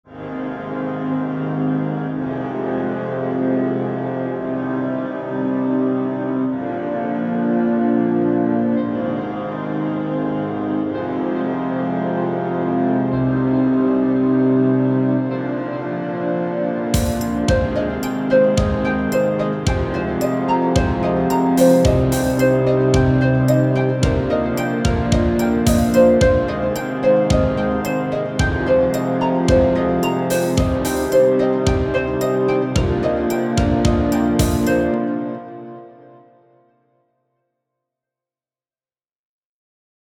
Extrait - Harpe
musique_harp.mp3